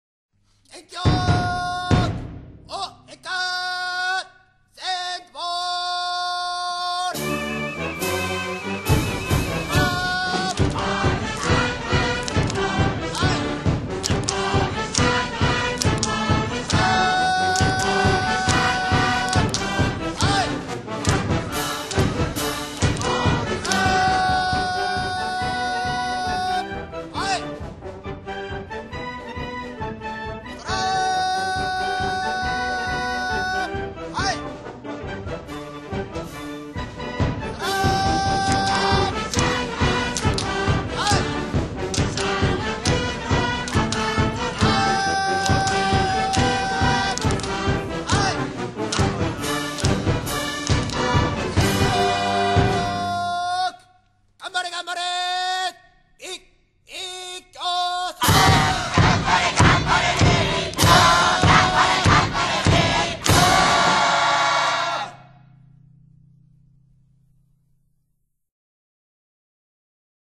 ちなみに、「When the sun goes down and the moon comes up」のフレーズが早口すぎて歌えません。